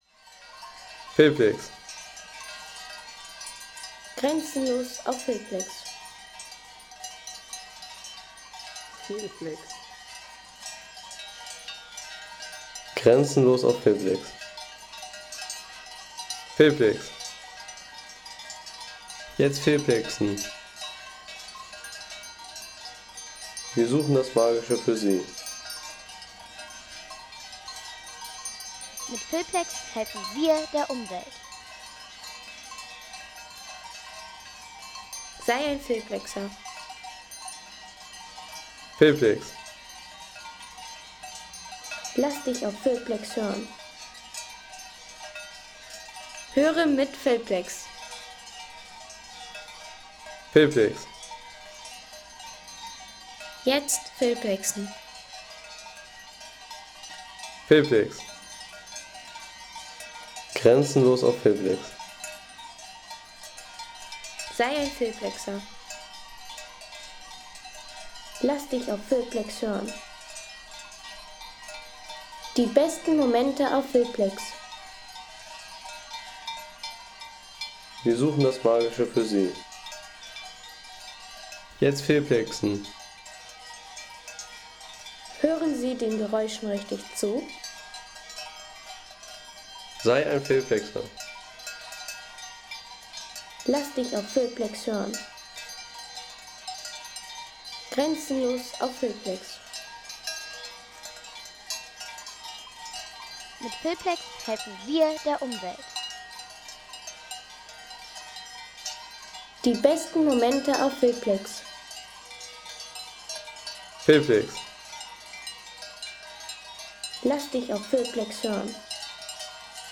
Kuhglocken - Tarrenton Alm
Das Konzert der Kühe – Klangvolle Almidylle in den Lechtaler Alpen.